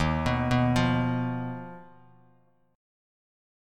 Ebsus2#5 chord